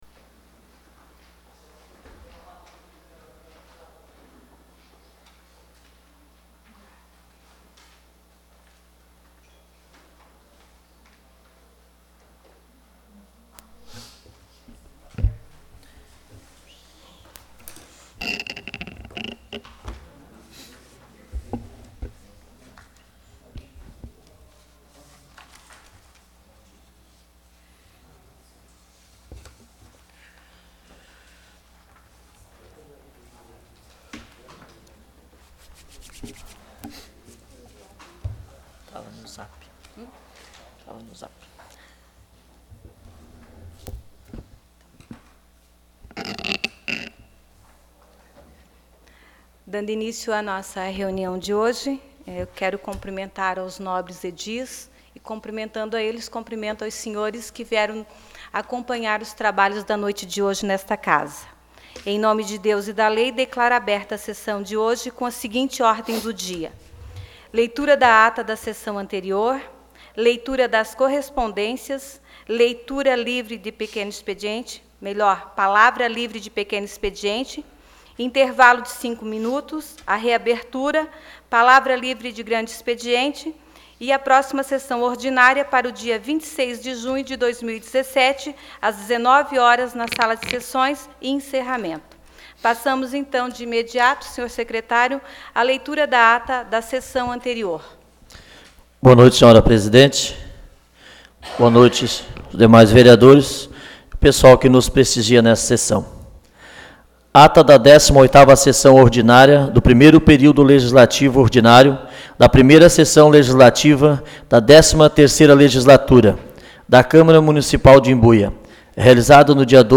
Áudio da Sessão Ordinária de 19 de junho 2017